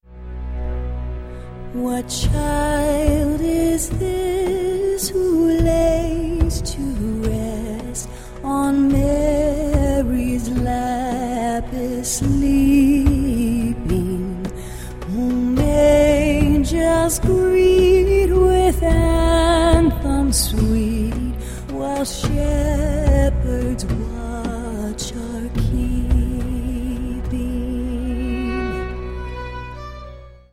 • Sachgebiet: Advent/Weihnachten Musik (Christmas)